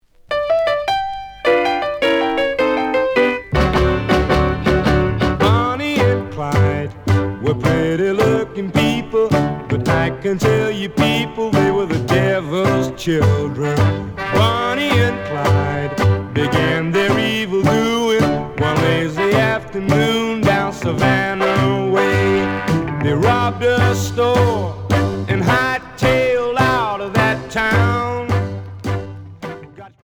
試聴は実際のレコードから録音しています。
●Format: 7 inch
●Genre: Rock / Pop